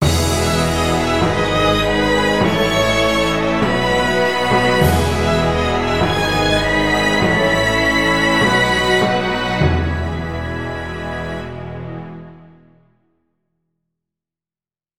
Victory Celebration Movie Score